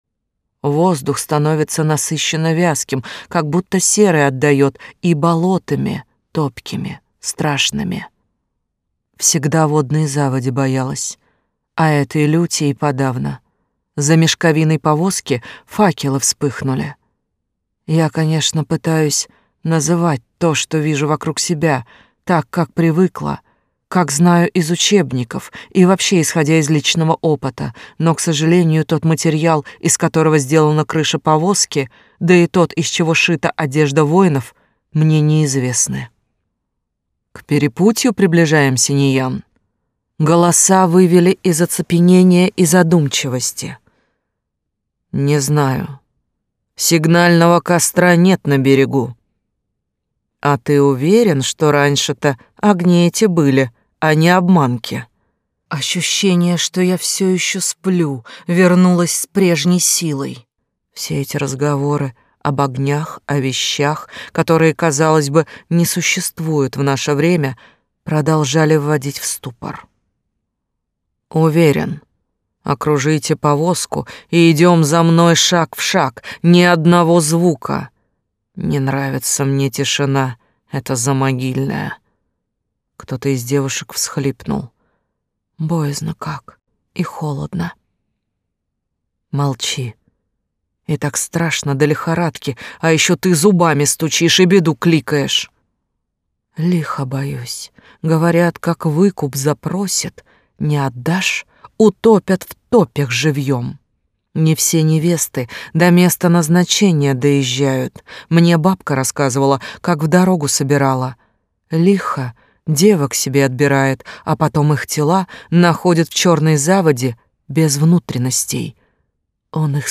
Аудиокнига Проклятие Черного Аспида (полная версия из двух частей) | Библиотека аудиокниг